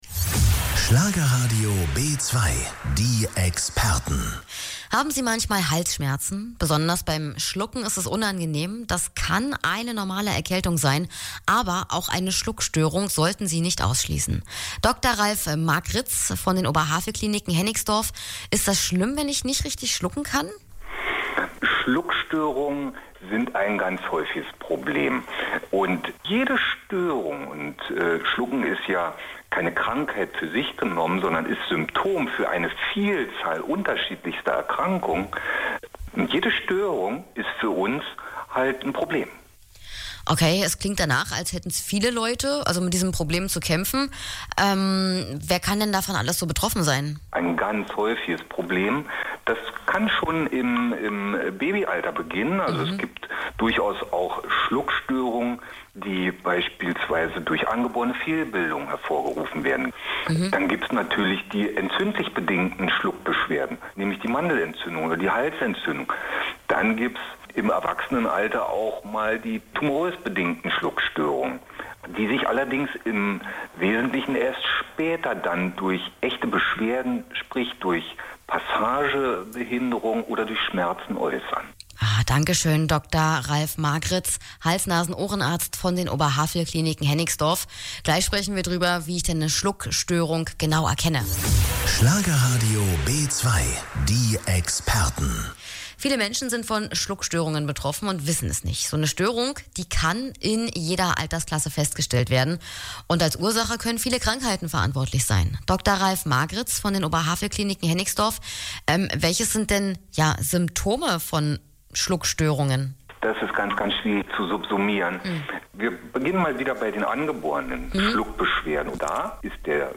im Interview bei Radio B2.